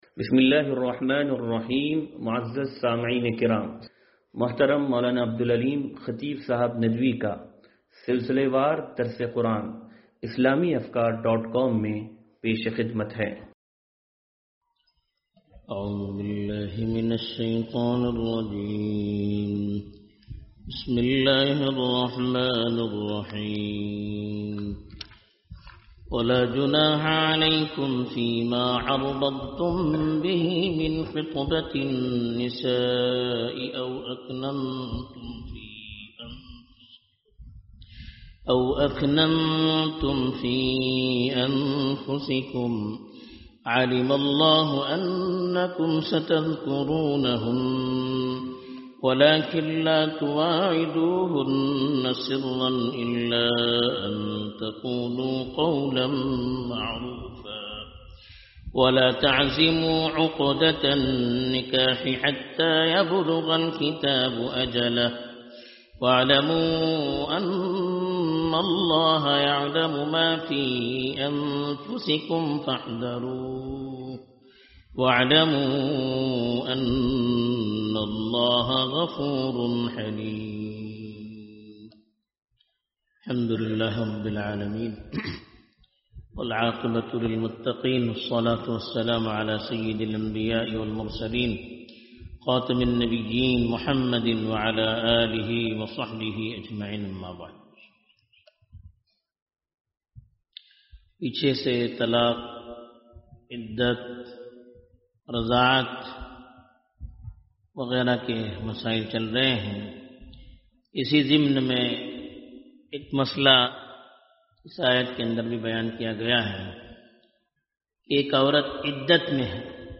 درس قرآن نمبر 0181